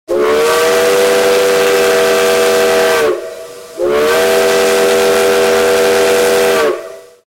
Toby Three Chime Steam Whistle